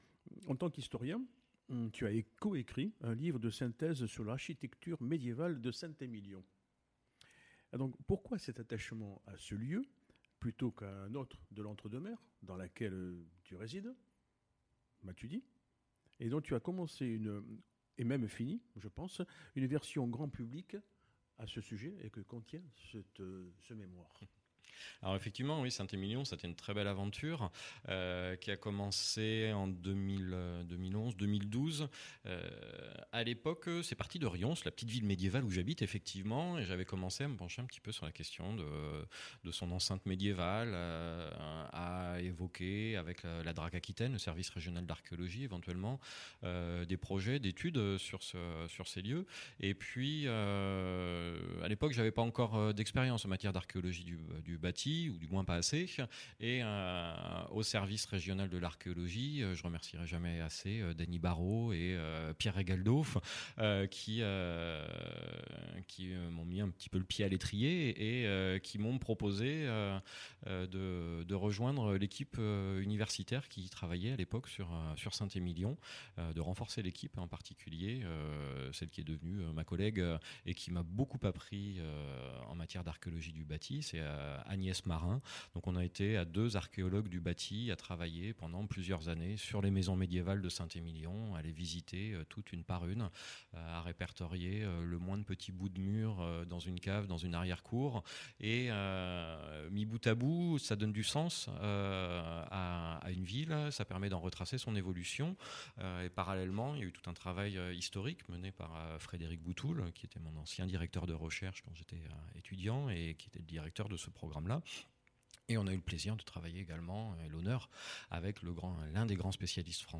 Portrait radio